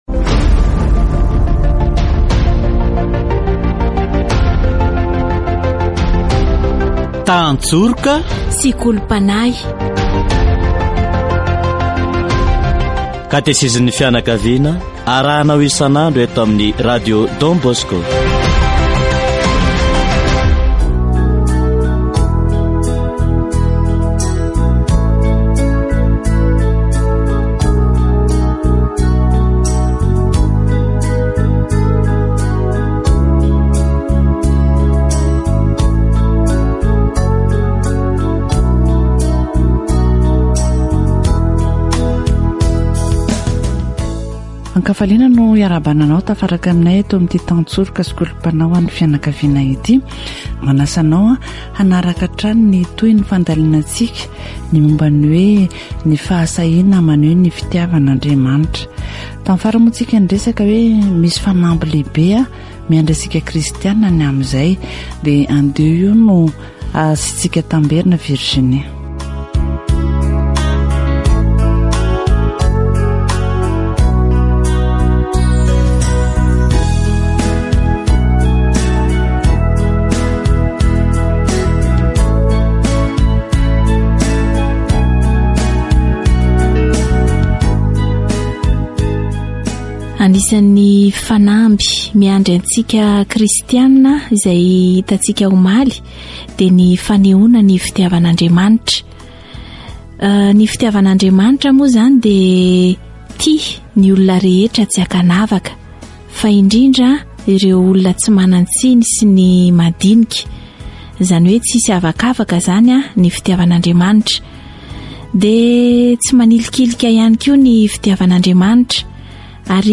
Endriky ny fitiavan'Andriamanitra isika rehetra eo amin'izay hatao, mila atambatra ny hery hiasa ho fanehoana izany fitiavana izany. Katesizy momba ny fitiavan'Andriamanitra